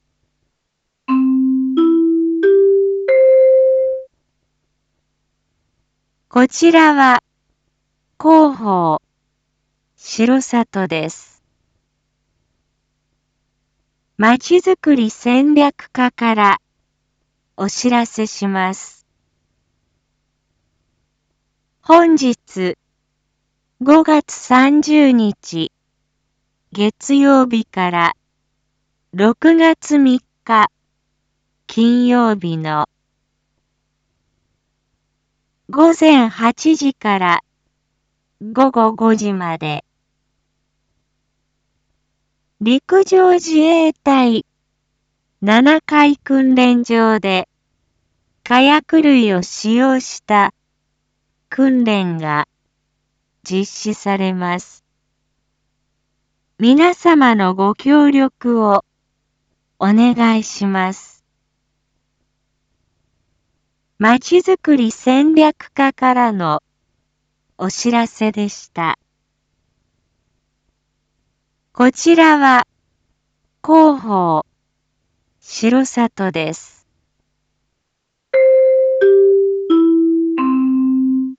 Back Home 一般放送情報 音声放送 再生 一般放送情報 登録日時：2022-05-30 07:01:22 タイトル：R4.5.30 7時放送 インフォメーション：こちらは広報しろさとです。